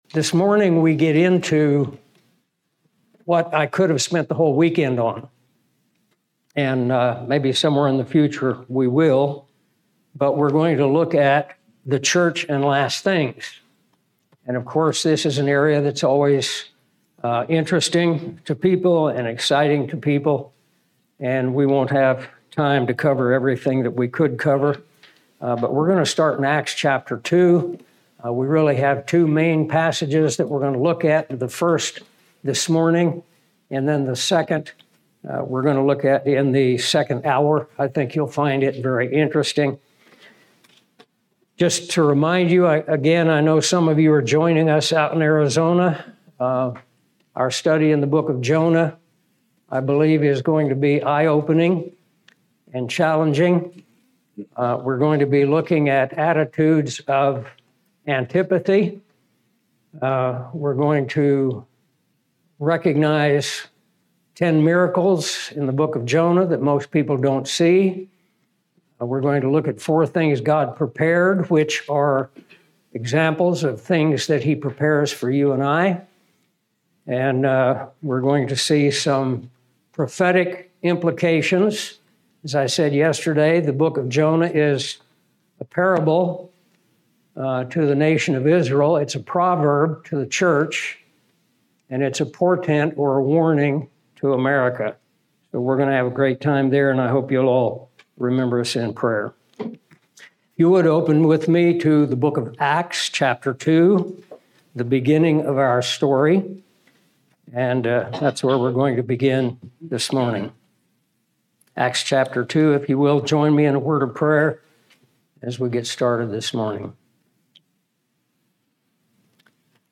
This Bible conference featured seven lesson extracted from The Basics Book.